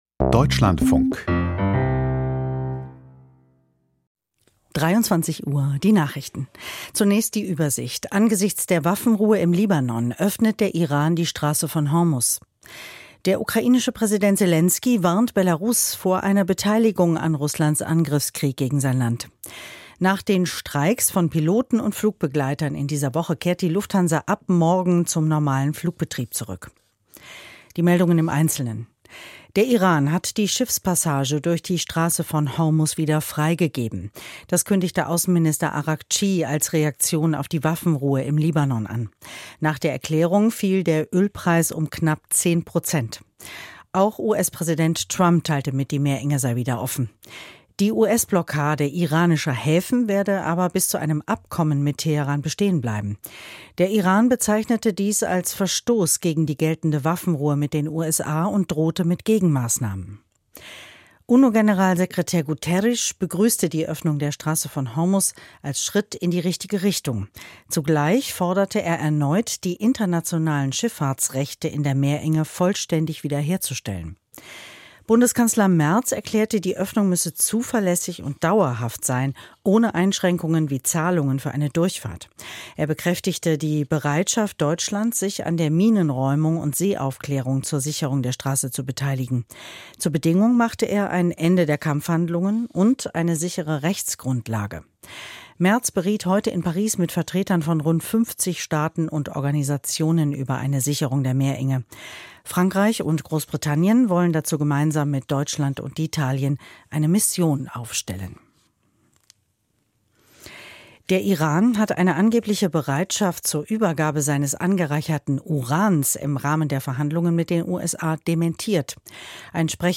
Die Nachrichten vom 17.04.2026, 23:00 Uhr
Aus der Deutschlandfunk-Nachrichtenredaktion.